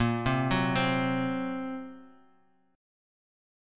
Bbsus2#5 chord